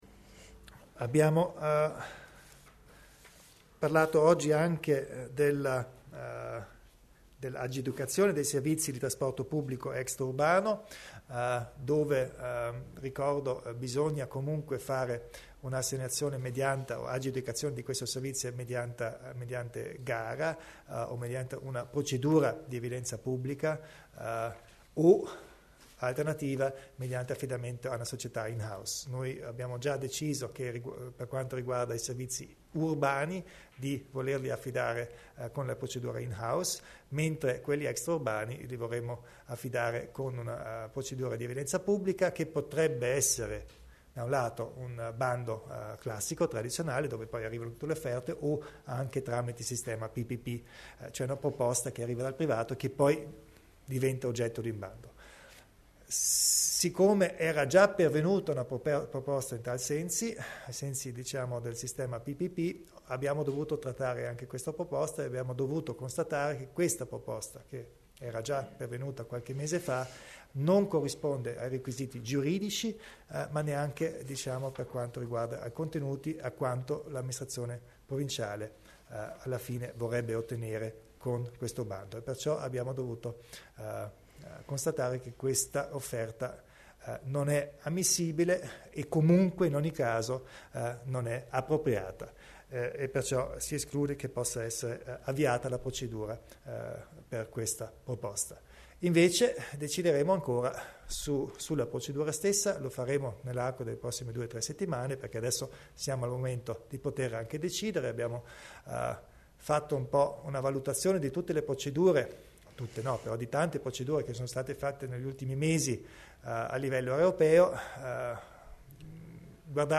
Il Presidente Kompatscher spiega le procedure per le assegnazioni dei servizi di trasporto pubblico